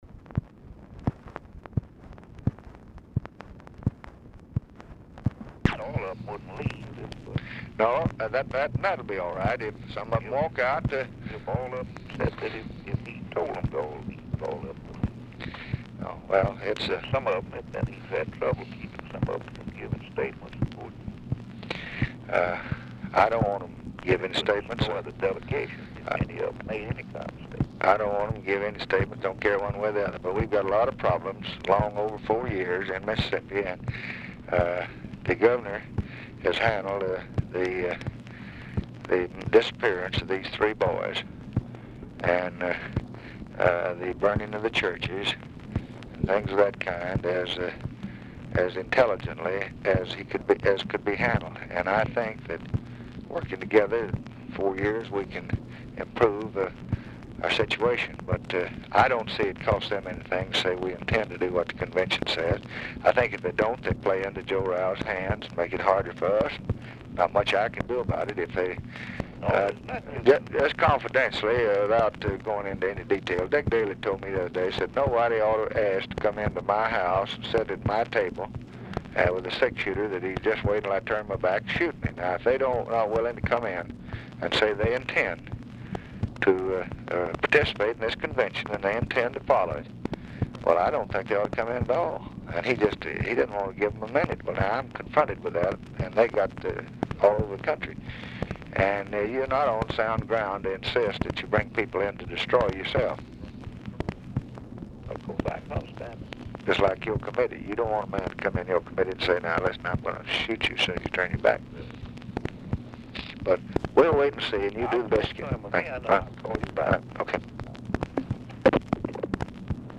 Telephone conversation
EASTLAND IS IN KENTUCKY, DIFFICULT TO HEAR
Format Dictation belt